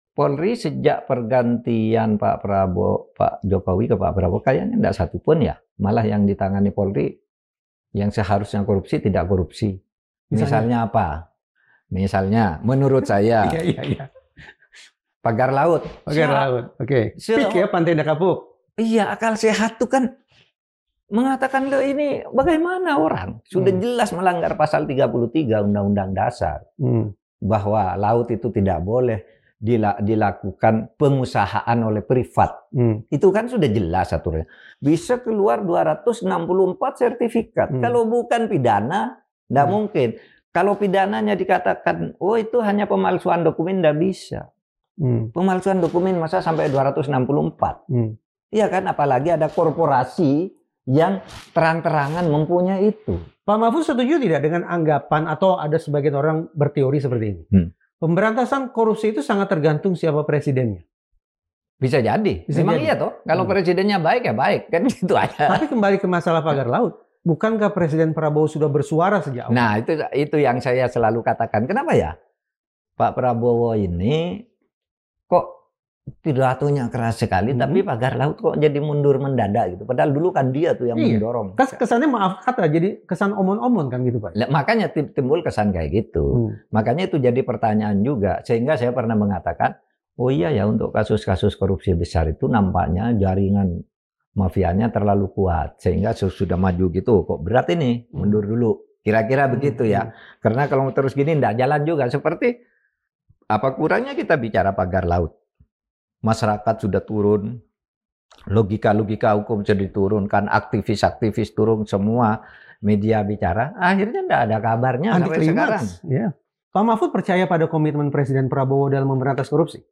Narasumber: Prof. Mahfud MD - Menteri Koordinator Bidang Politik, Hukum, dan Keamanan 2019-2024